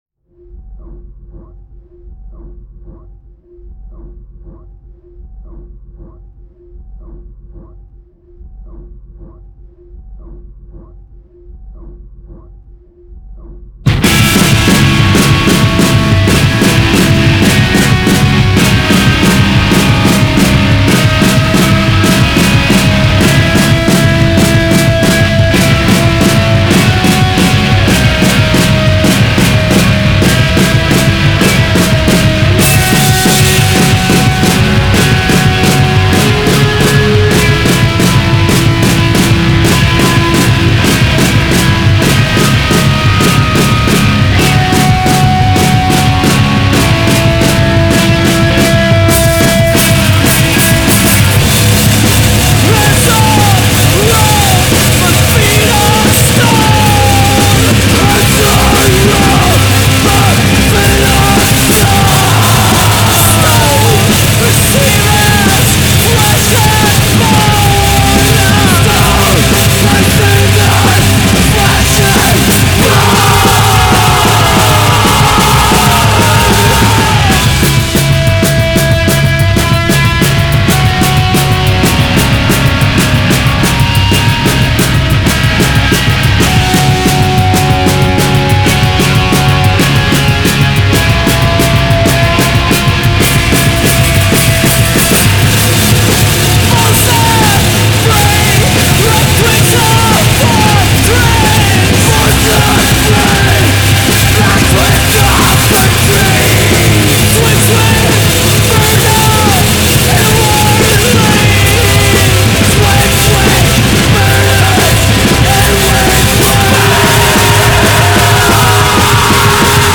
Attention les décibels !
post hardcore